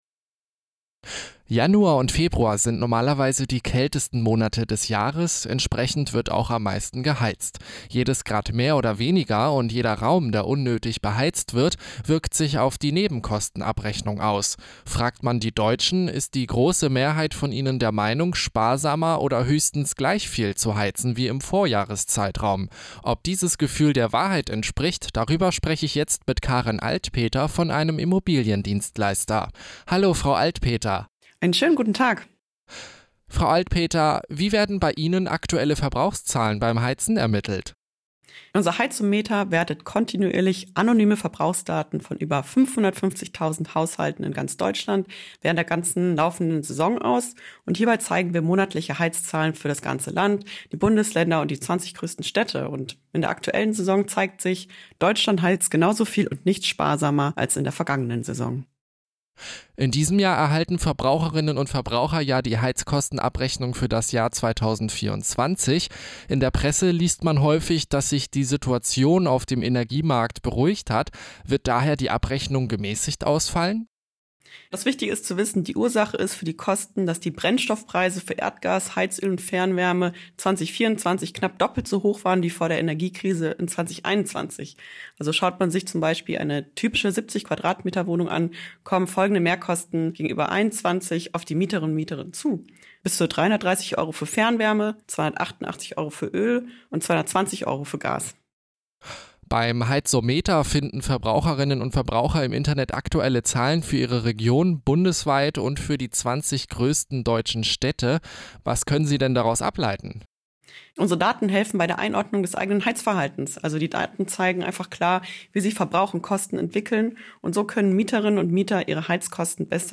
Heizkostenschock: Mehrheit der Deutschen unterschätzt ihre Heizkosten. Ein Interview